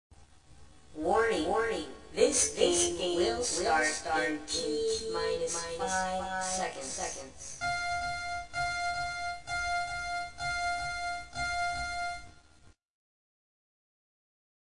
Countdown.mp3
countdown.mp3